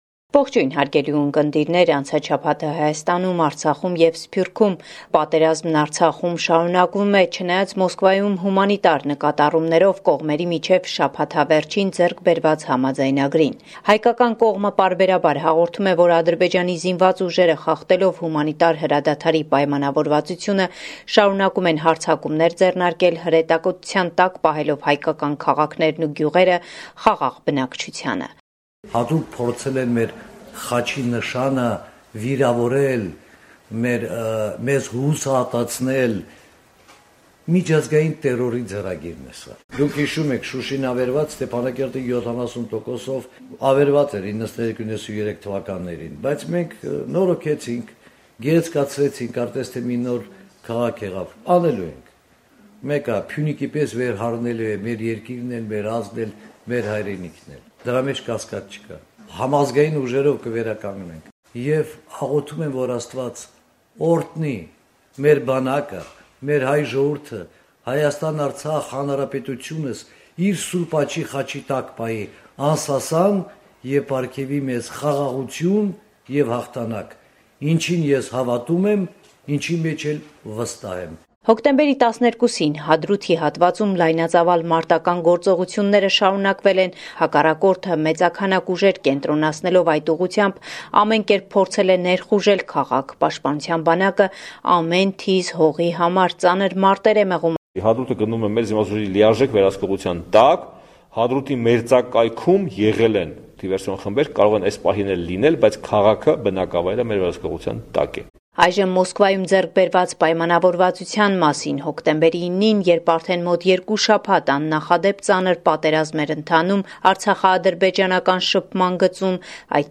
Latest News from Armenia – 13 October 2020